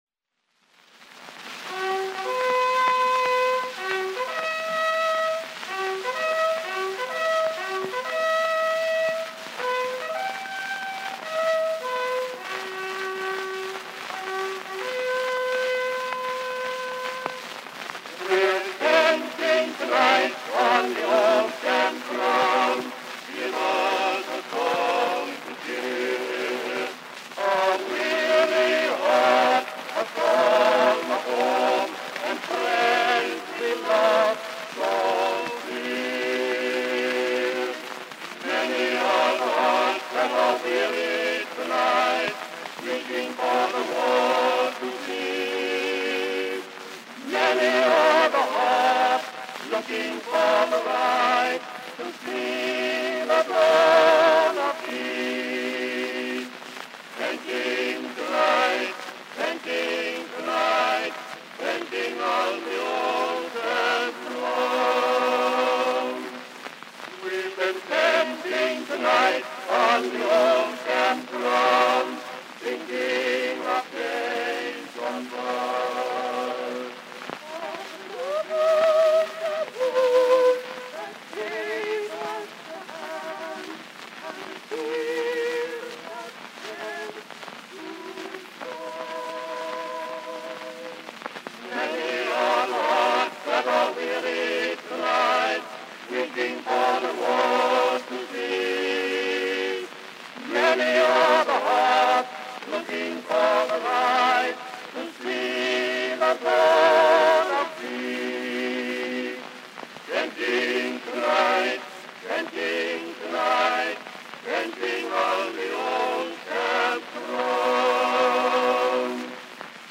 Nice Edison 4-minute cylinder…“We’re Tenting Tonight”
Preformed by “Knickerbocker Quartette” Cylinder number is # 618.